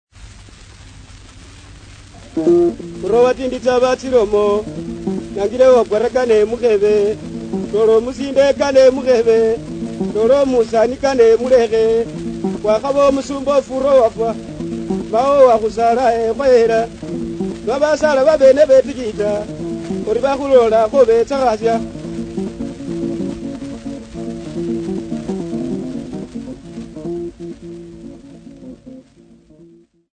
Popular music--Africa
Field recordings
Africa Uganda Mbale f-ug
Topical song with Lidungu harp